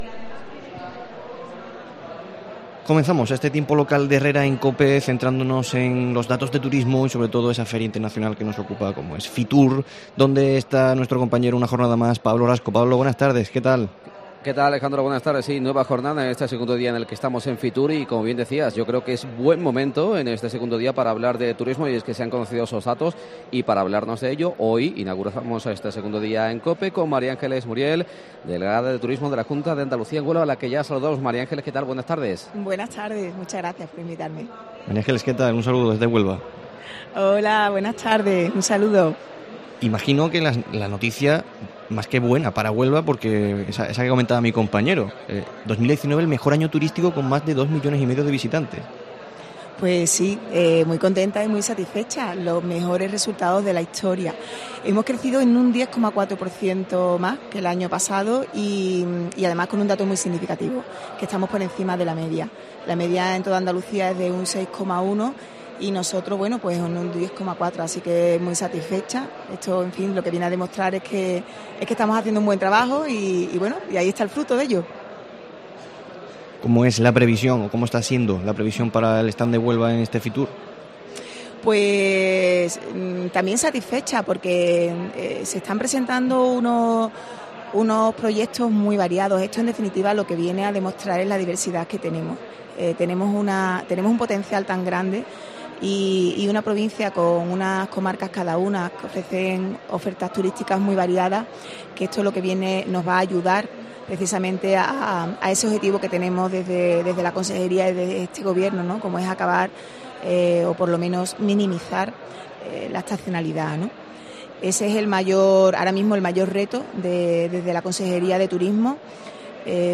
La delegada territorial de Turismo, María Ángeles Muriel, comparece para COPE Huelva desde FITUR donde analiza y se congratula de los datos turísticos.